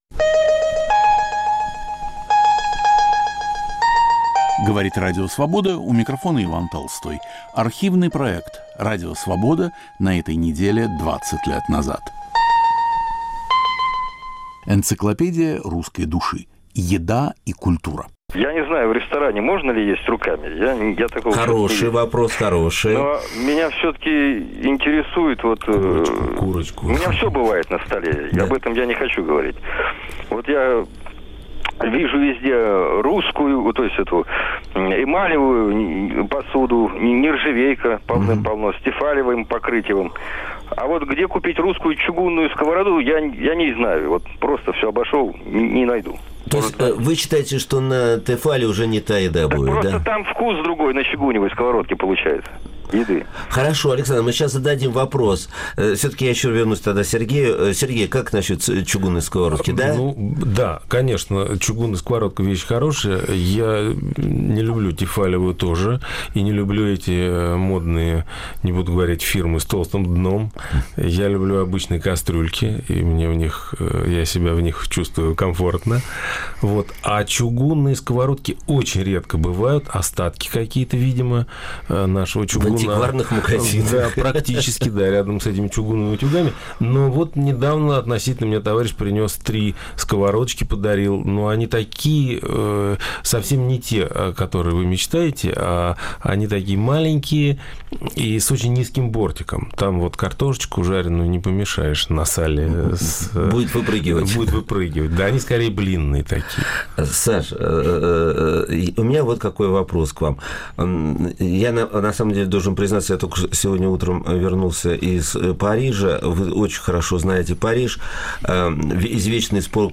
Передачу подготовил и ведет Виктор Ерофеев.